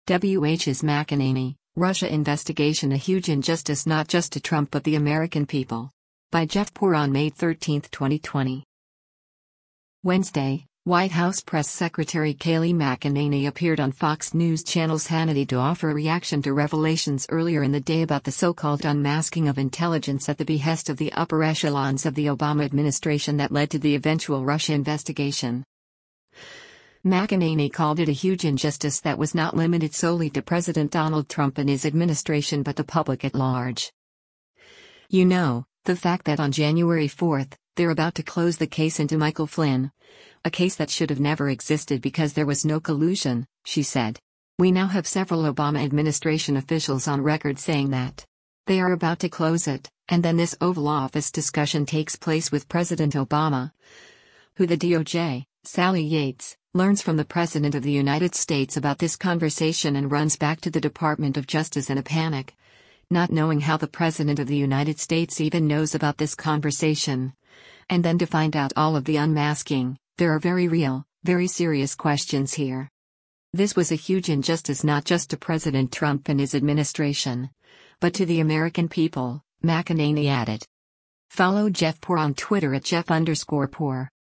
Wednesday, White House press secretary Kayleigh McEnany appeared on Fox News Channel’s “Hannity” to offer a reaction to revelations earlier in the day about the so-called “unmasking” of intelligence at the behest of the upper echelons of the Obama administration that led to the eventual Russia investigation.